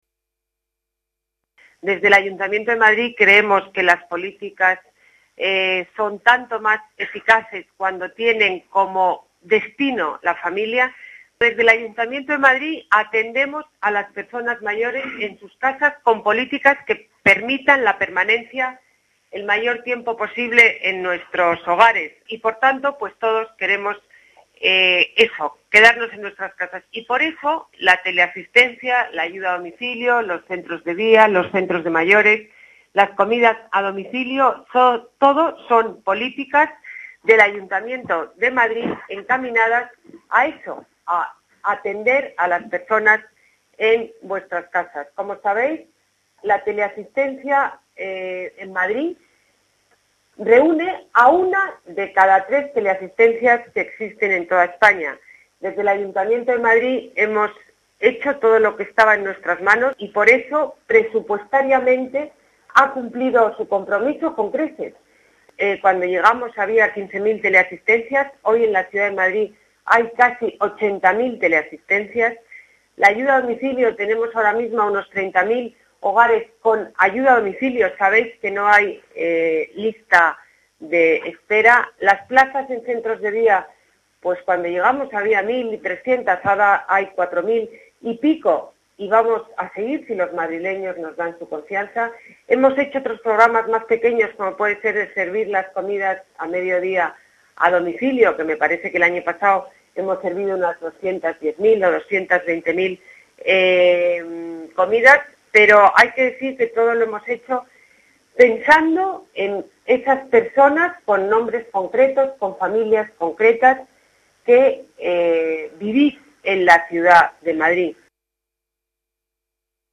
Nueva ventana:Ana Botella, en la inauguración de este nuevo centro, ha recordado algunos de los programas para mayores realizados por el Ayuntamiento de Madrid